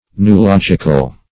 Noological \No`o*log"ic*al\, a. Of or pertaining to noology.